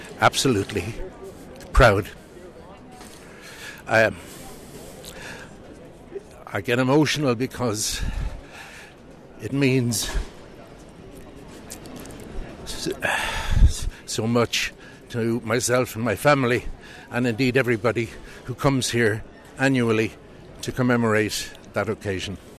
Thousands attended the event at the GPO on O’Connell Street, including the Taoiseach, Tánaiste, Lord Mayor and President Michael D. Higgins.